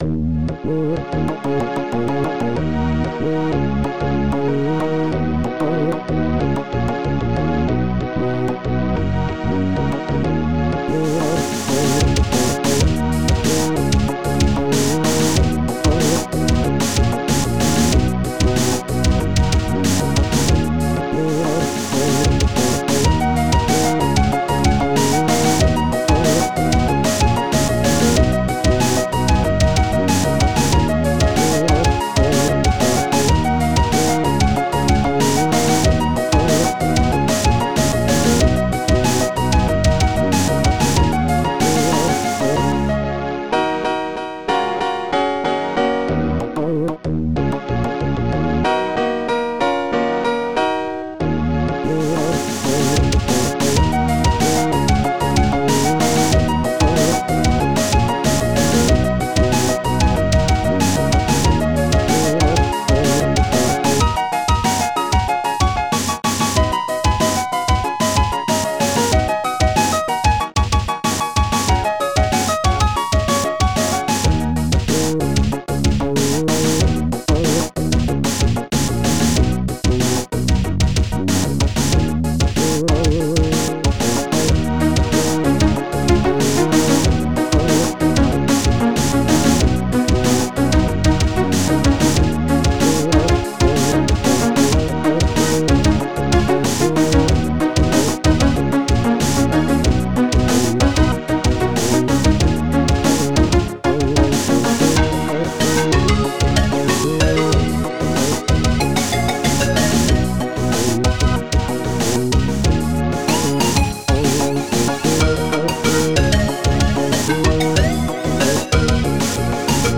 Protracker Module